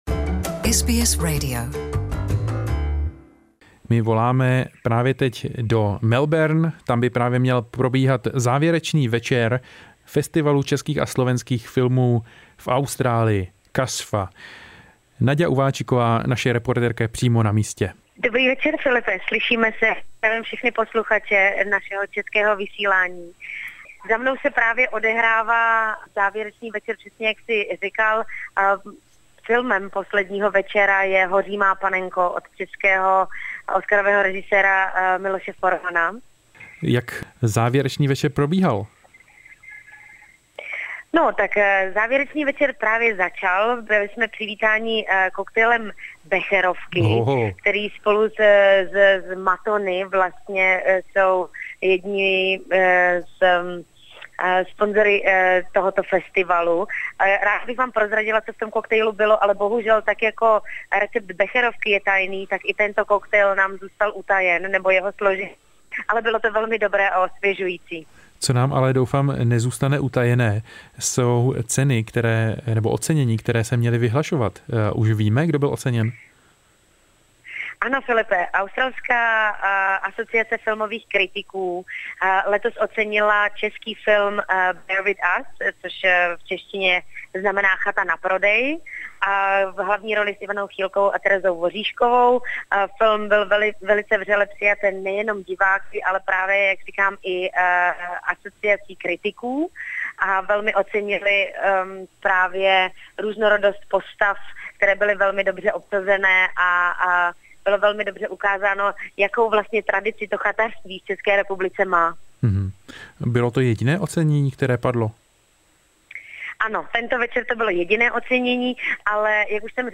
in ACMI Melbourne for the Closing Night